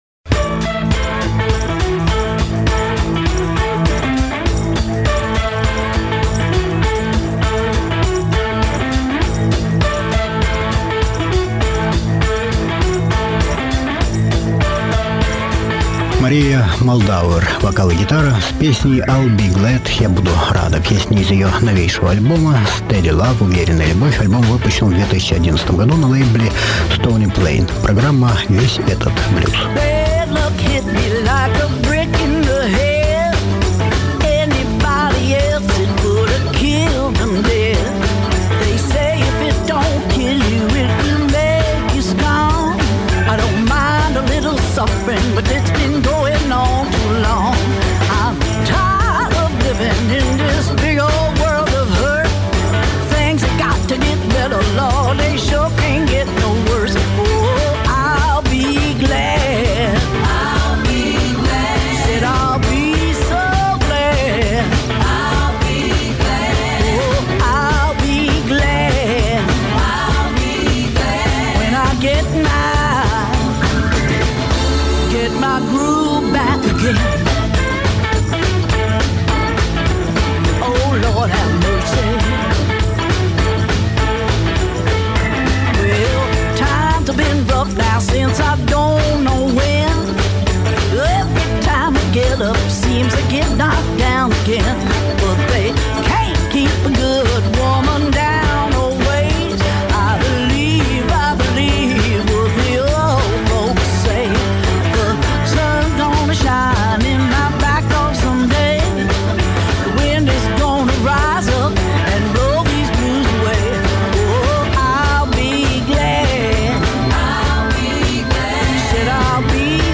блюзовая гитаристка
гитарист и вокалист
блюзовый гитарист, автор и исполнитель в стиле "соул"